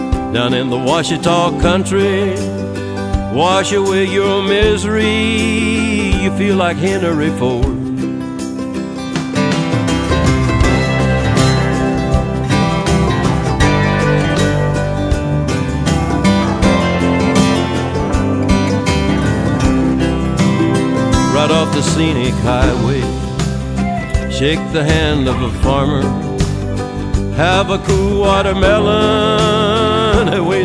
Guitare
country music